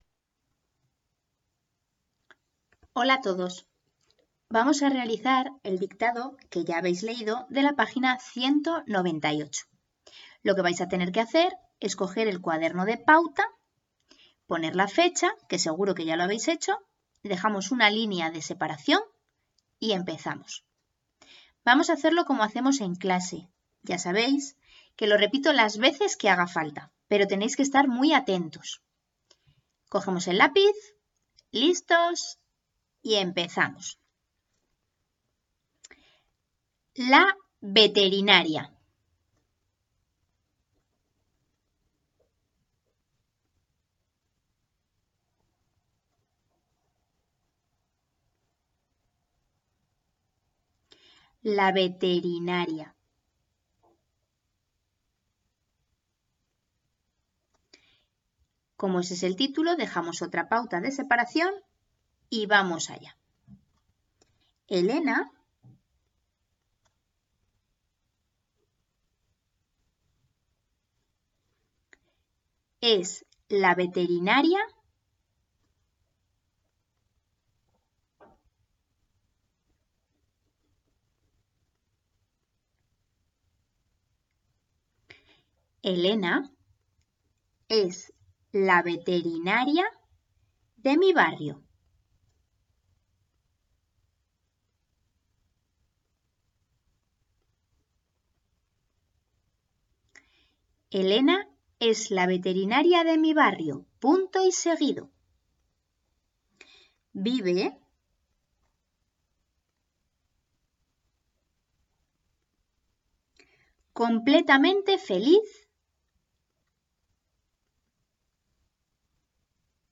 Dictado pág. 198
1_Dictado_Lengua_pagina_198.mp3